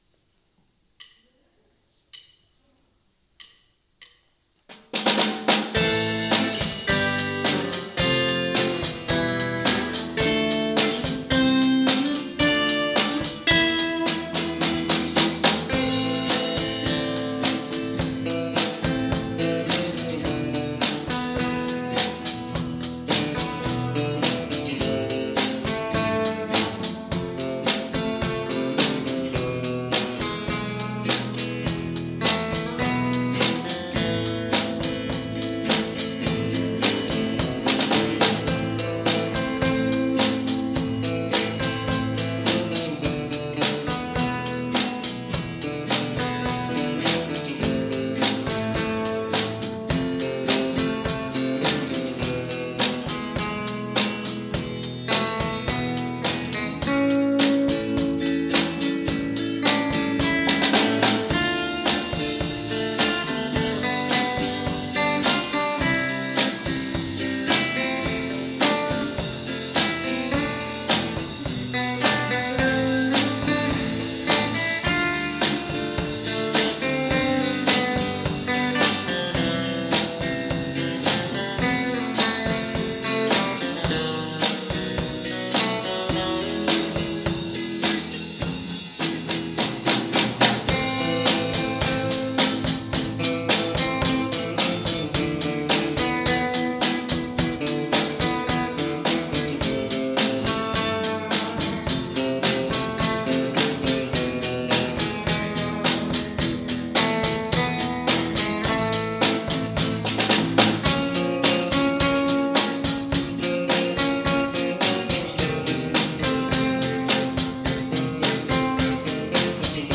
桂病院土曜コンサート
場所：桂病院外来棟玄関ホール
何時もと演奏環境が違い 少し戸惑っています